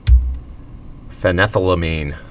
(fĕnĕthə-lə-mēn, -lămən)